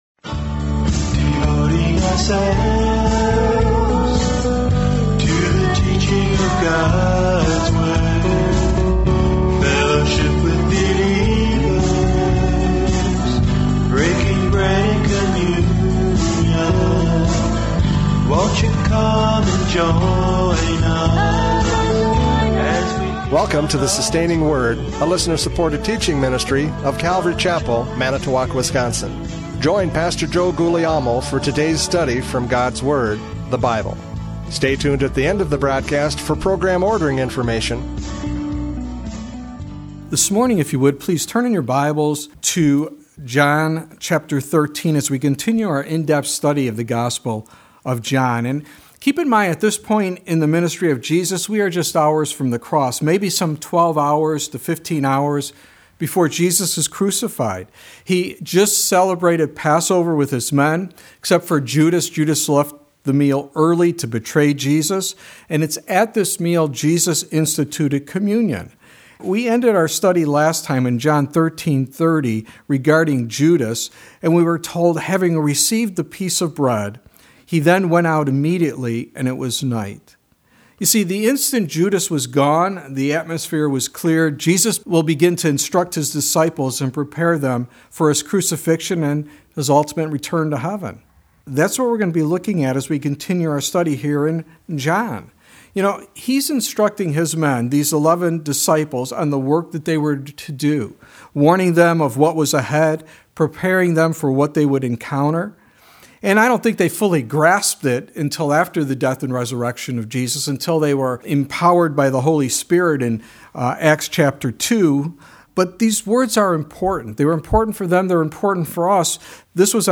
John 13:31-38 Service Type: Radio Programs « John 13:21-30 The Betrayer!